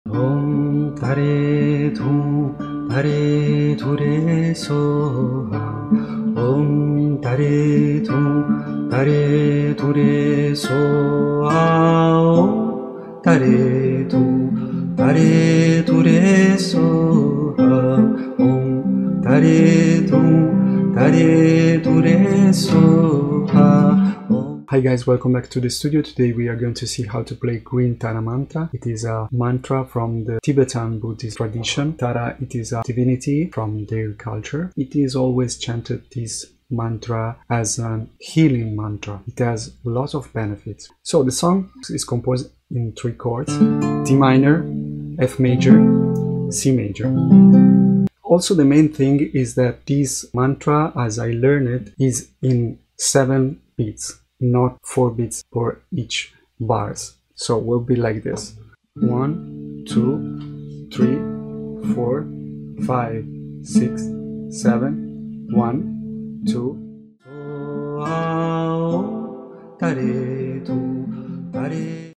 on Guitar